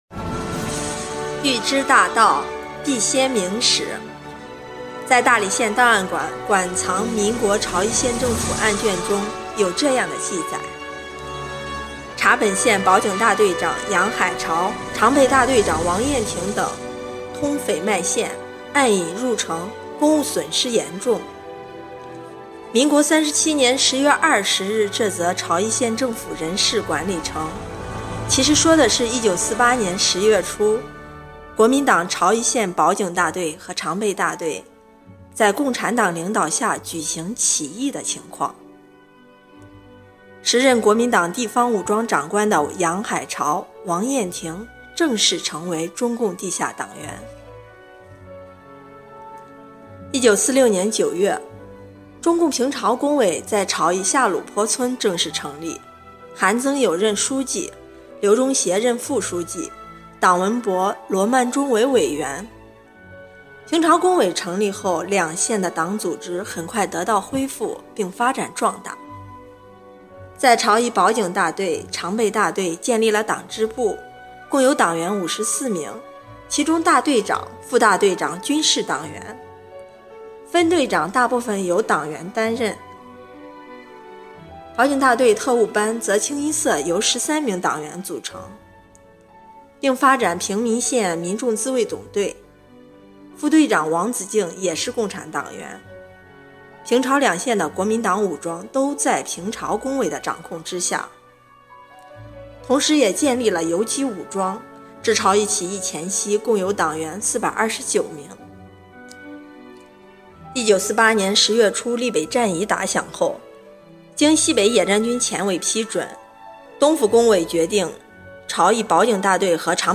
【红色档案诵读展播】档案里的朝邑起义